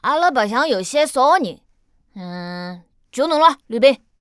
序章与第一章配音资产
c01_5残疾小孩_1.wav